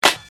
Claps
nt clap 1.wav